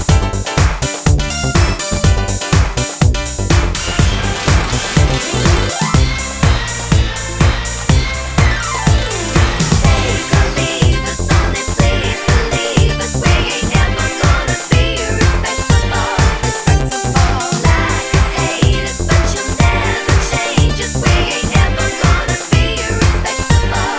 Backing Vocals Reduced In Volume Pop (1980s) 3:17 Buy £1.50